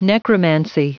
Prononciation du mot necromancy en anglais (fichier audio)
Prononciation du mot : necromancy